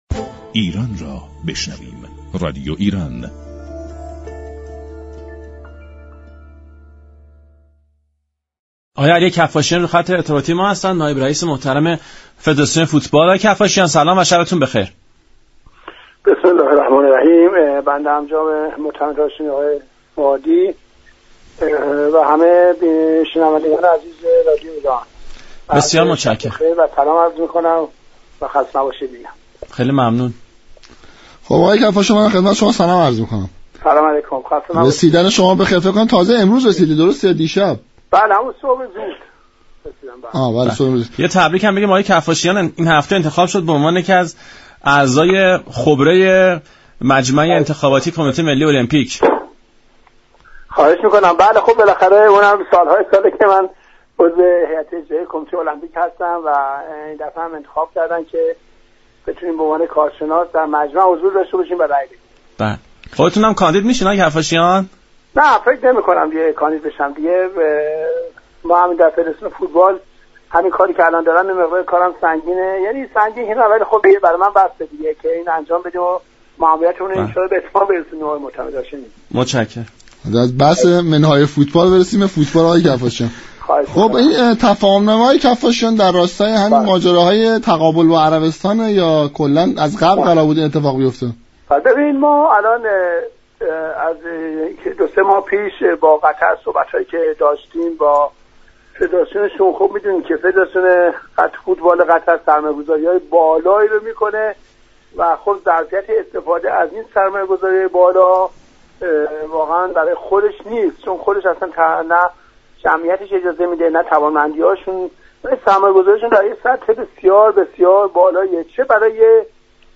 در گفت و با برنامه «وقت اضافه»